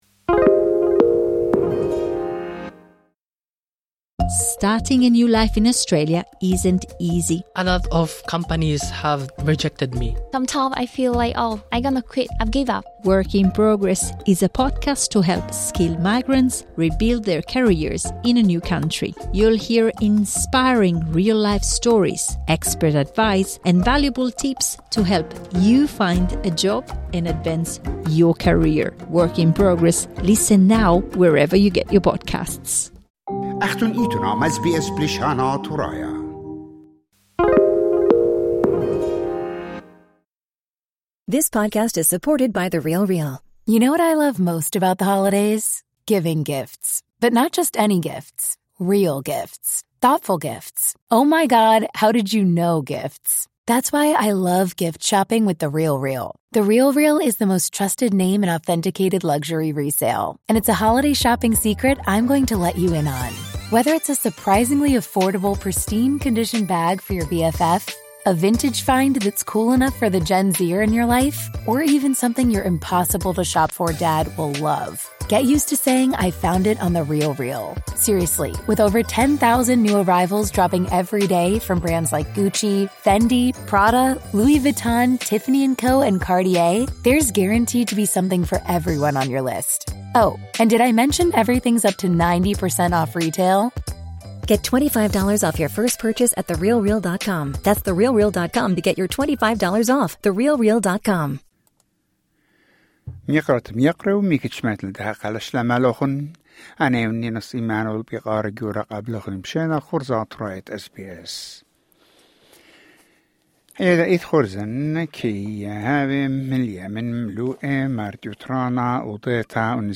News Bulletin: 2 December 2025